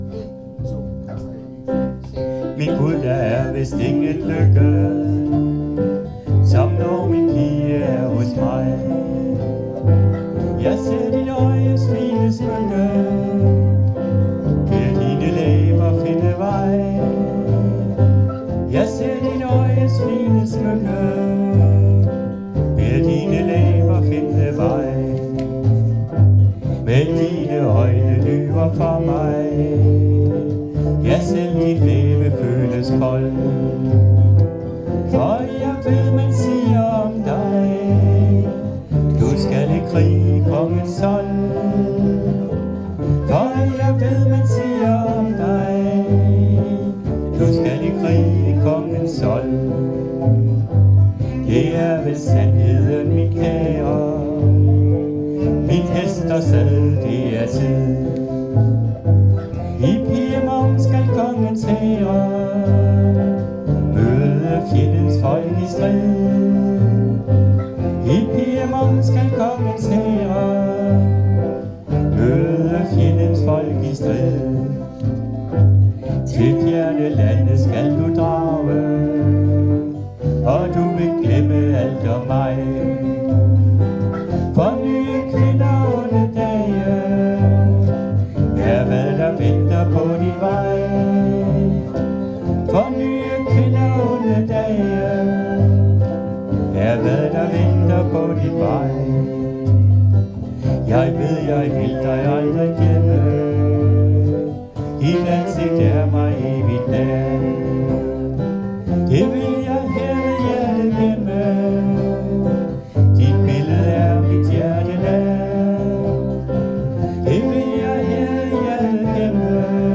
Øveaften 23. oktober 2024: